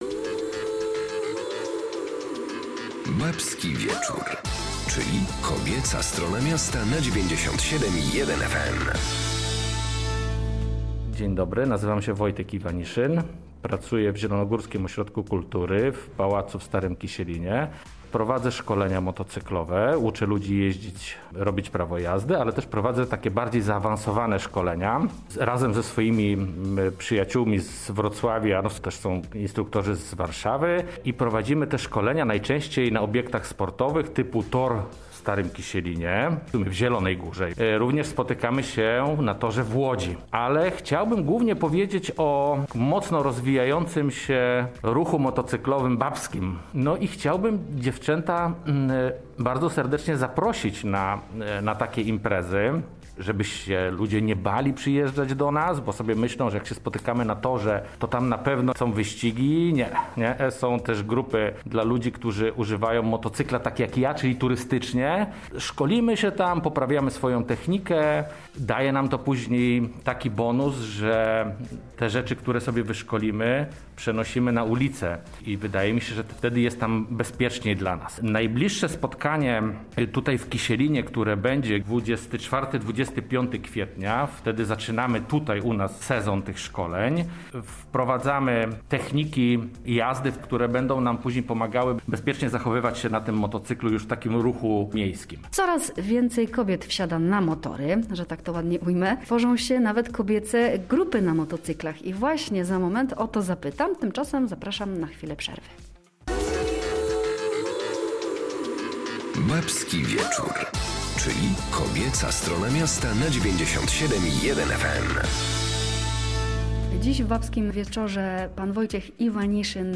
Jak pokazują badania z USA motocykle to coraz częściej wybierany przez kobiety środek transportu i sposób na relaks. Także w Polsce powstaje coraz więcej babskich klubów motocyklowych. Zapraszam na rozmowę z nauczycielem bezpiecznej techniki jazdy na motorze.